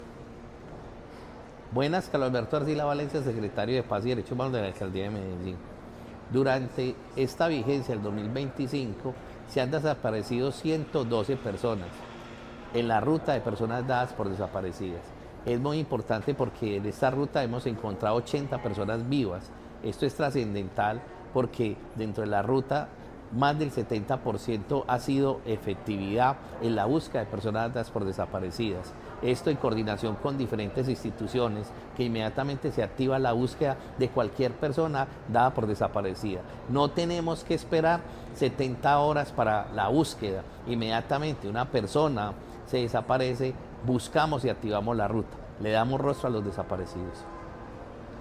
Palabras de Carlos Alberto Arcila, secretario de Paz y Derechos Humanos